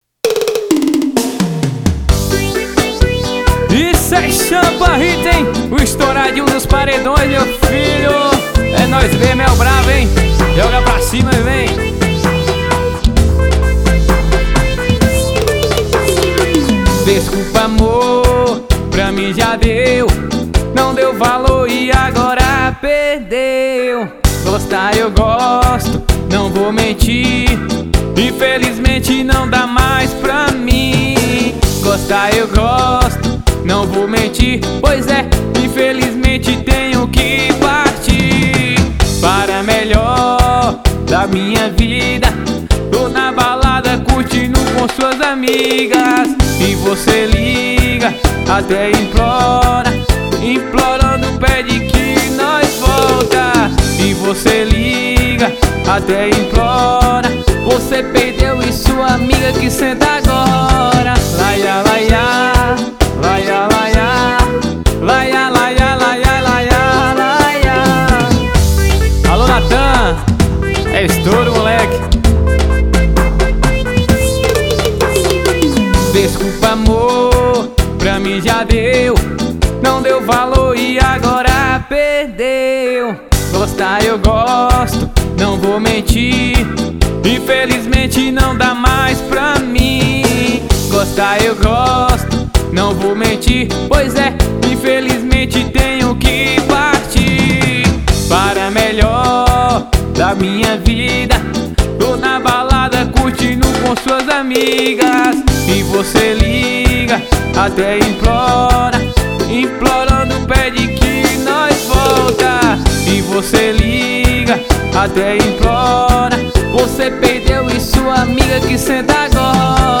EstiloArrochadeira